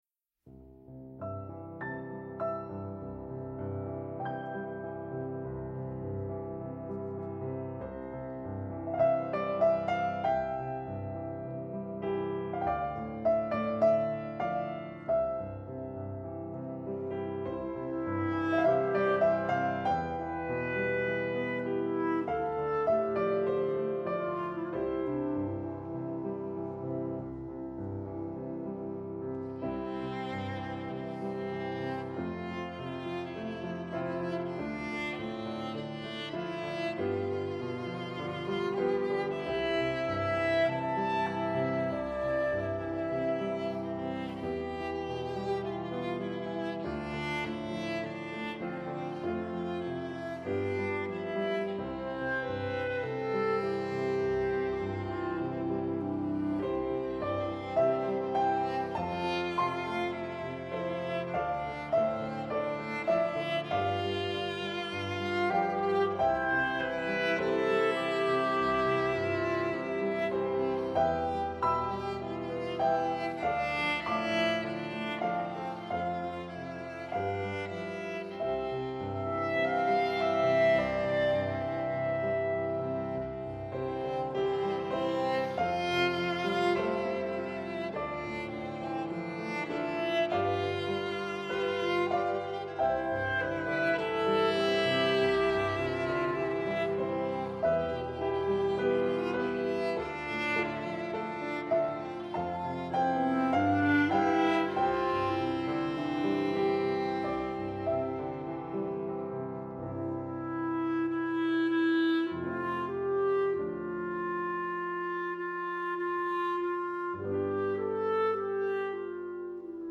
Clarinet
Viola
Piano